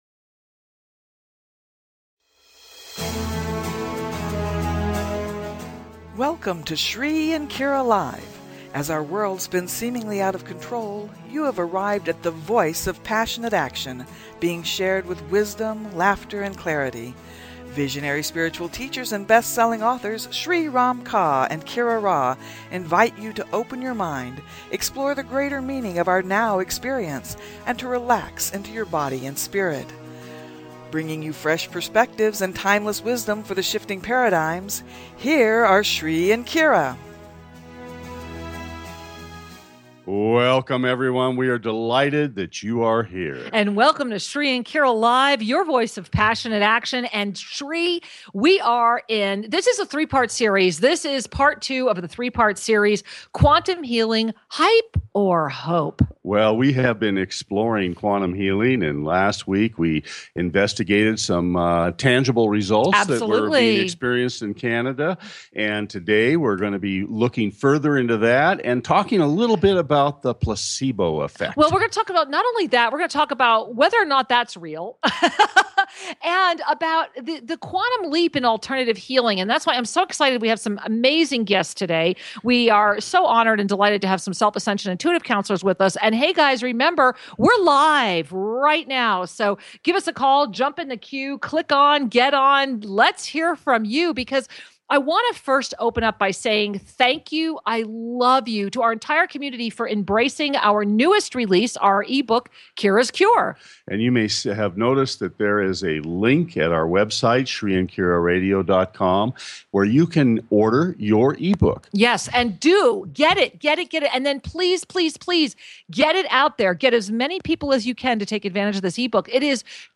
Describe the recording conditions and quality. Live call in for questions, comments or a mini-soul reading!